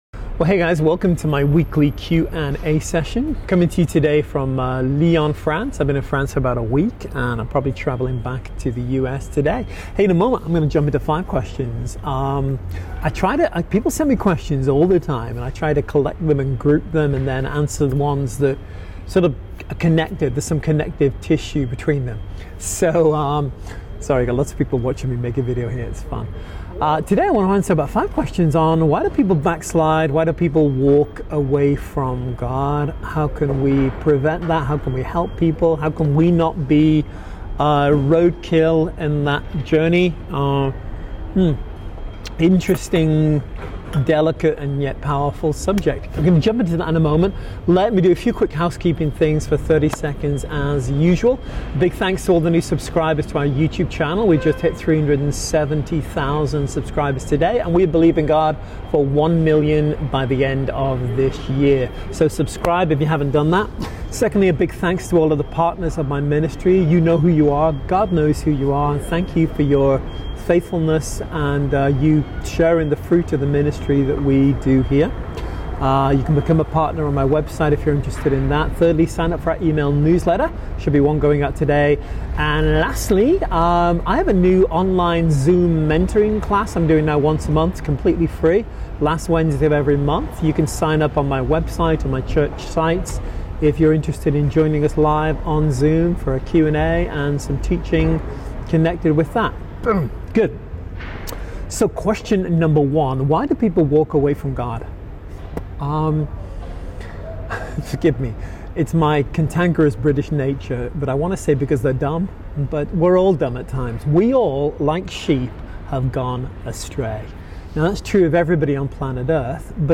Q&A Session